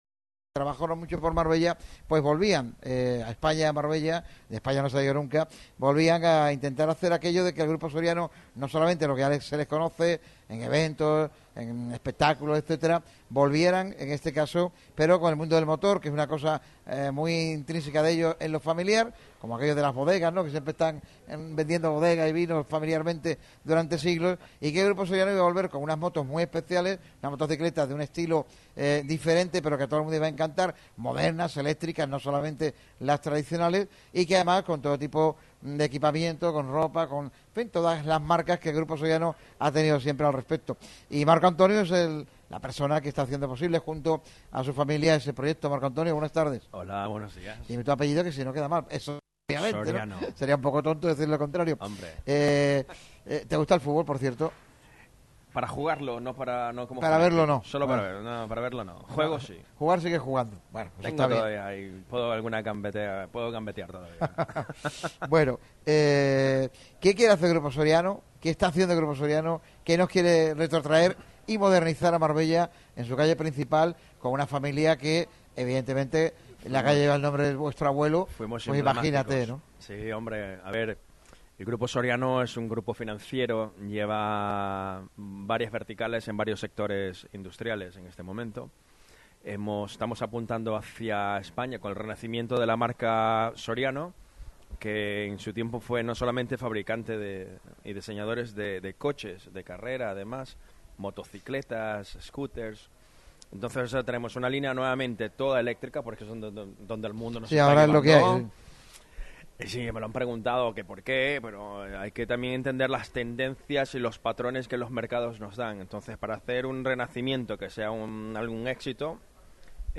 Otro lunes de sabor, otro lunes de Araboka en Radio MARCA Málaga. Como cada principio de semana el programa se ha realizado Araboka Plaza, en las maravillosas instalaciones de Araboka Plaza con su sede en la calle Compositor Lehmberg Ruiz, 28.